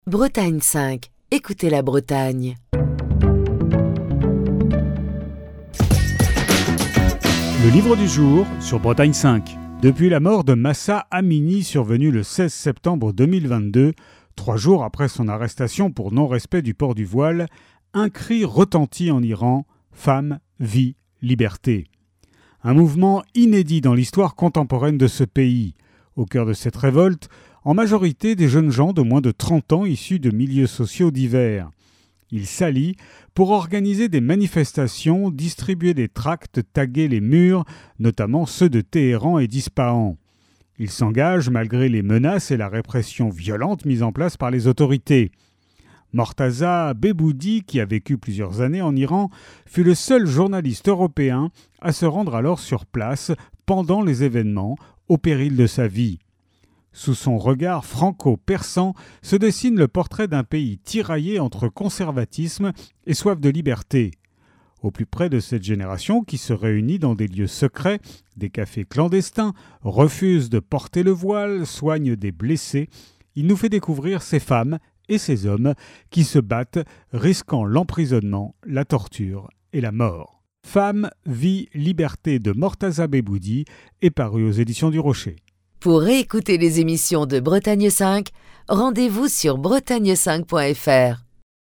Chronique du 14 mai 2025.